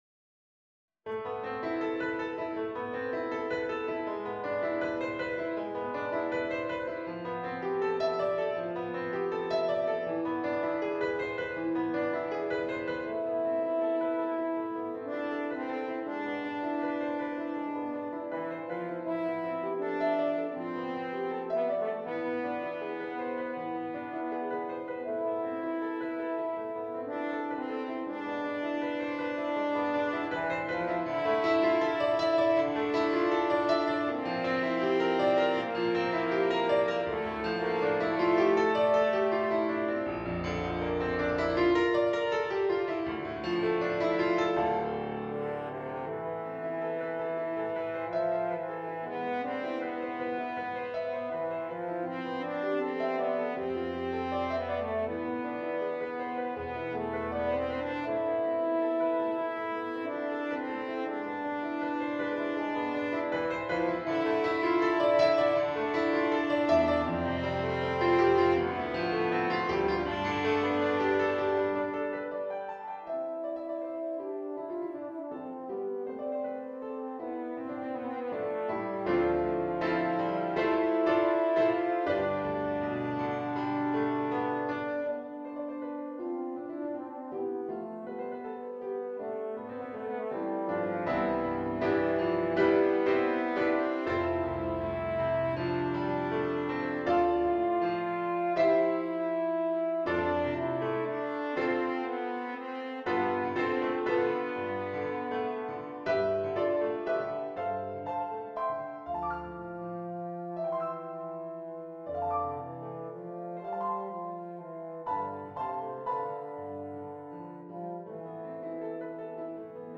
French Horn version
• Reference mp3 French Horn version